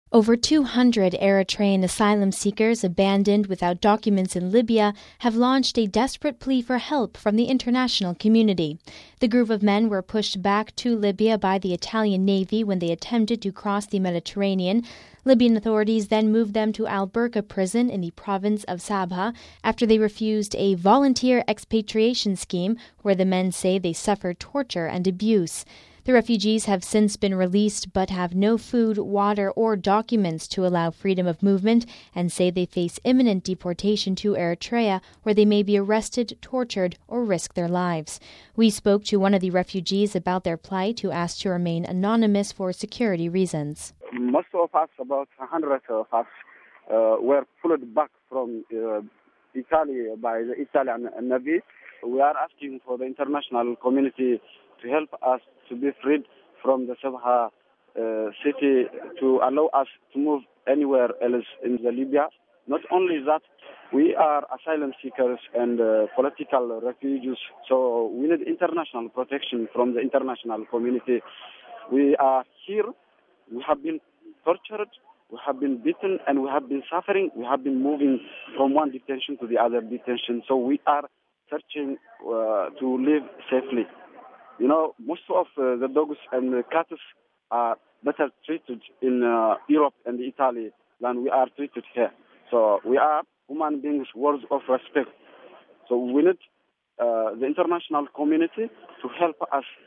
We spoke to one of the refugees about their plight who asked to remain anonymous for security reasons.